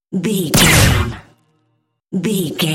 Dramatic stab laser
Sound Effects
Atonal
heavy
intense
dark
aggressive